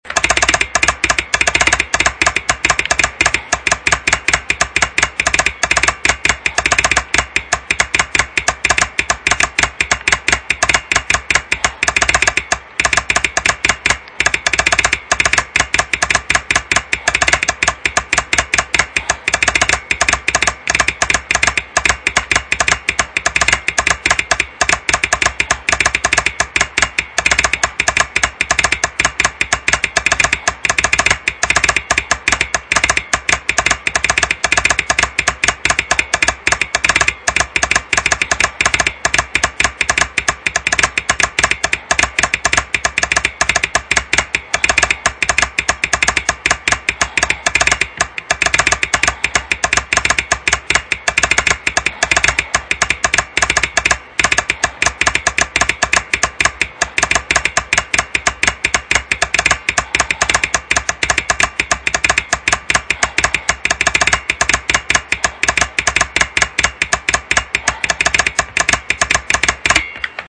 日本無線の電鍵ＫＹ−３Ａです。
ＫＹ−３Ａの打鍵音